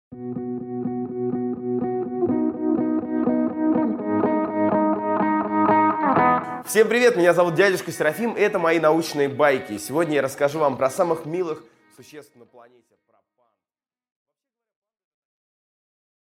Аудиокнига Панда | Библиотека аудиокниг
Прослушать и бесплатно скачать фрагмент аудиокниги